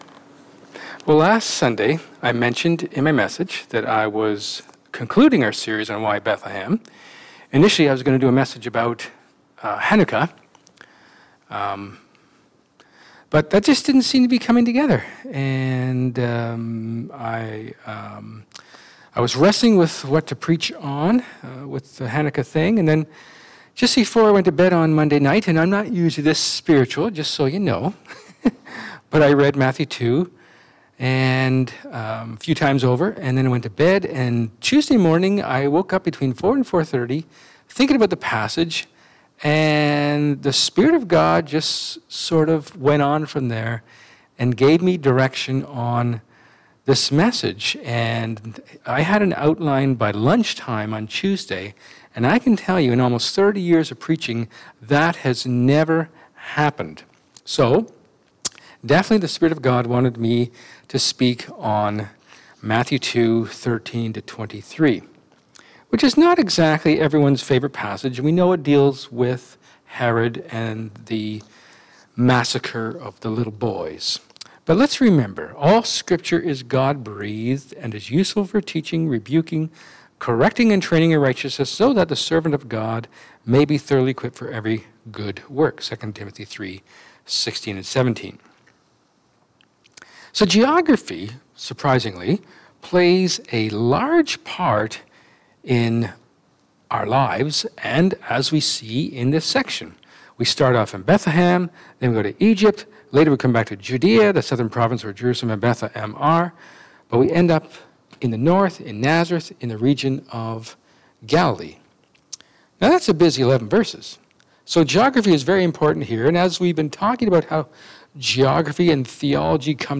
John 13:31-14:15 Service Type: Sermon